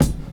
drum9.ogg